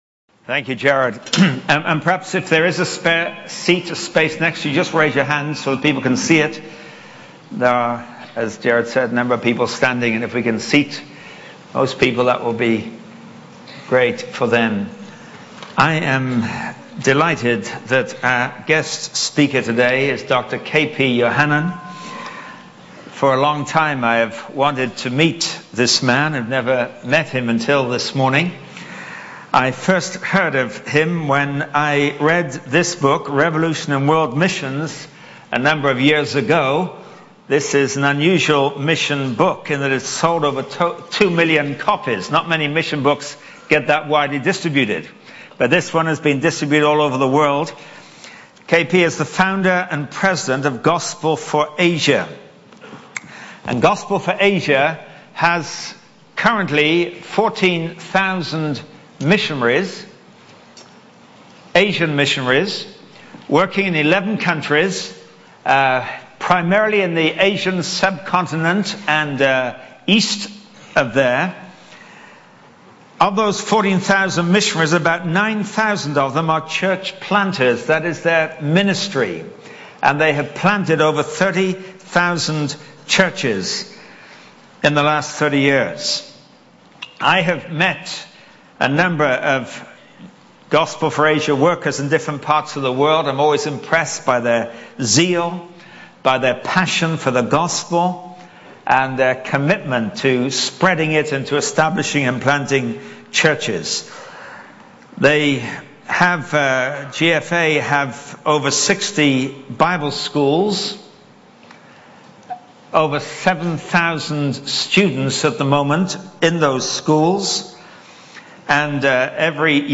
In this sermon, the speaker reflects on his personal journey of seeking to understand the humanity of Jesus by reading the four gospels.